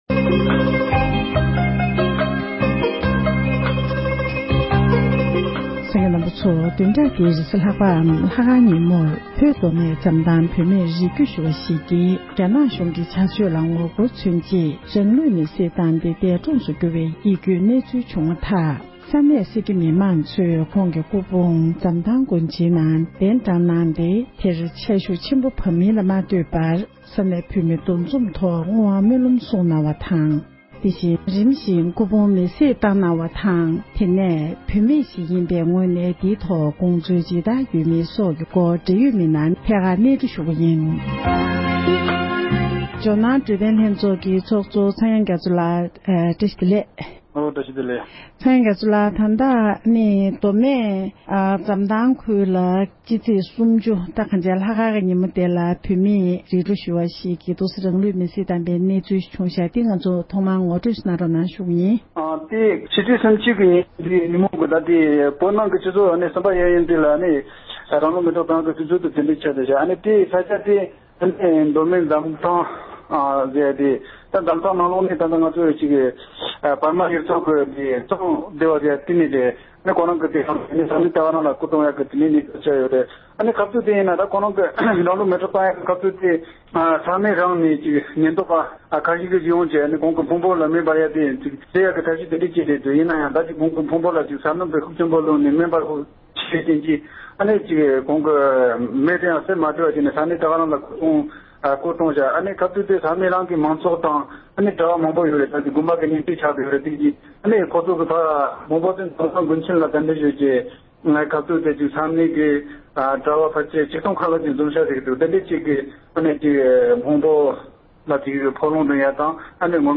འབྲེལ་ཡོད་མི་སྣར་ཐད་ཀར་ཞལ་པར་ཐོག་གནས་འདྲི་ཞུས་པ་ཞིག་གསན་རོགས་ཞུ༎